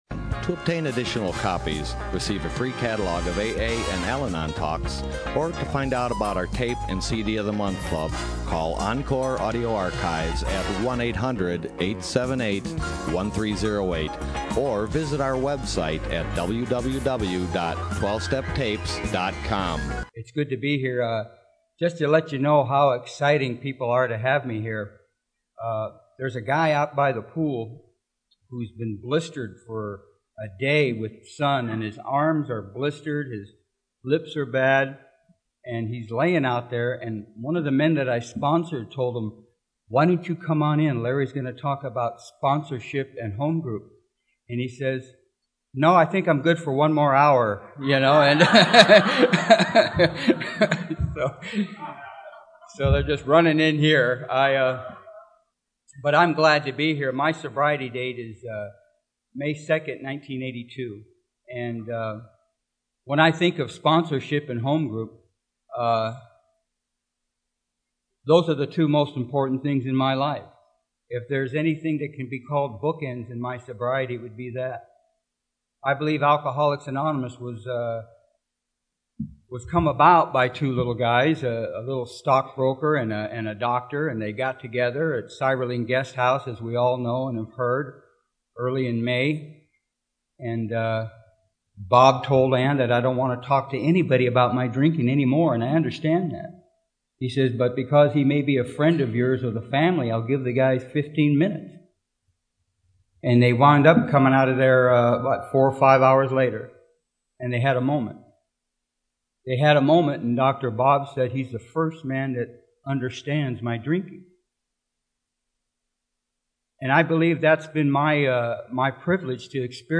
Desert POW WOW 2014